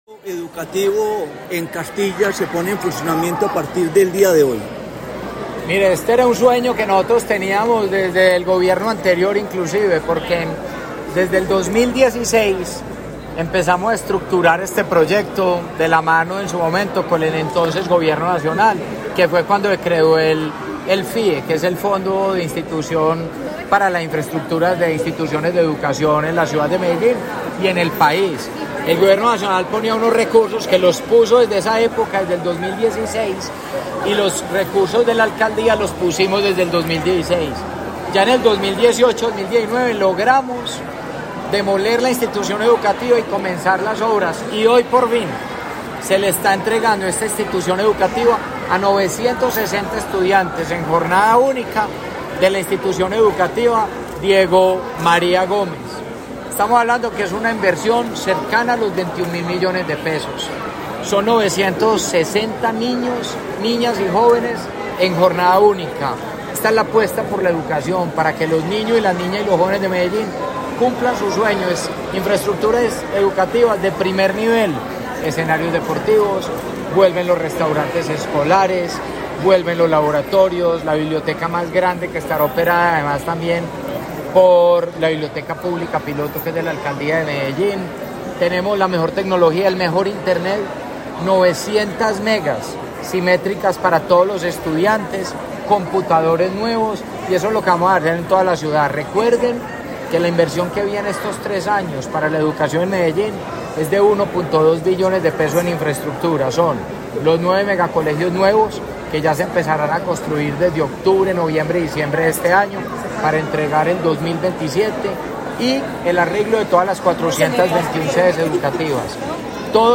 Palabras de Federico Gutiérrez Zuluaga, alcalde de Medellín
Alcalde-Renovacion-Escuela-Diego-Maria-Gomez-01.mp3